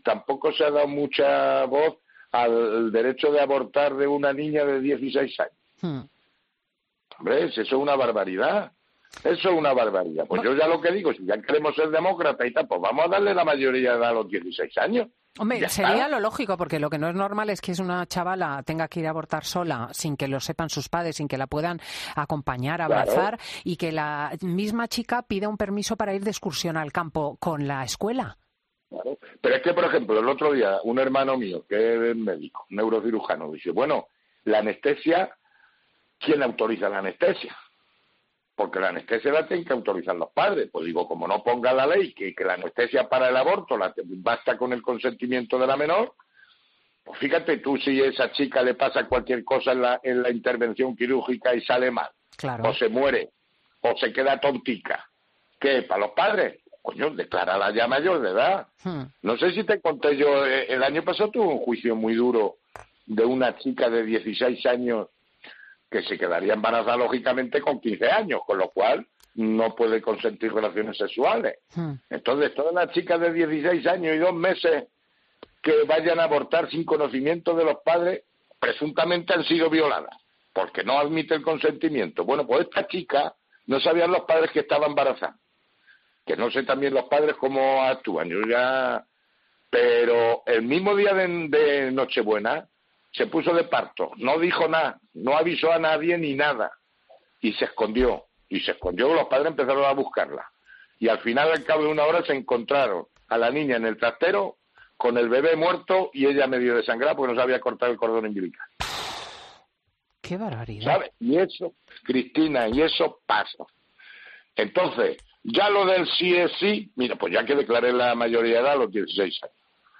En su intervención como colaborador de 'Fin de Semana' de COPE con Cristina López Schlichting, el magistrado subraya las contradicciones de la reforma de la ley de salud sexual y reproductiva y de interrupción voluntaria del embarazo que permite que una menor de 16 años pueda abortar sin el consentimiento de los padres, pero luego esa misma menor no puede comprar tabaco.